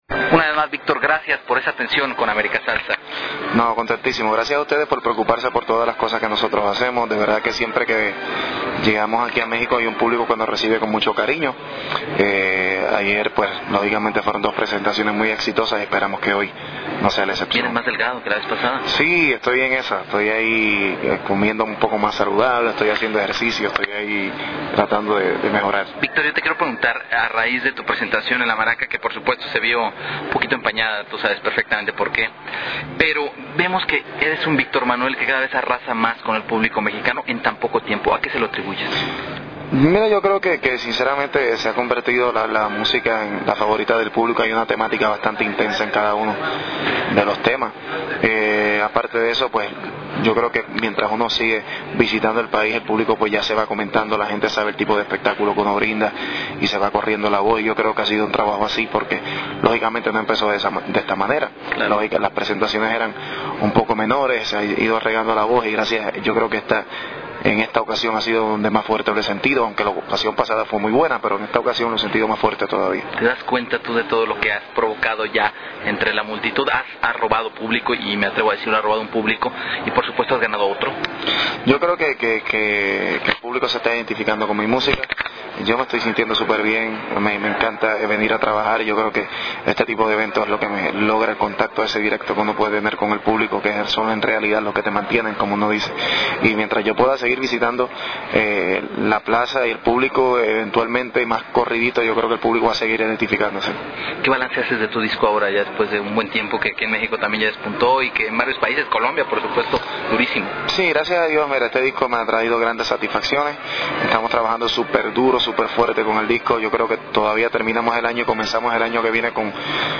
AUDIO Entevista Victor Manuelle.mp3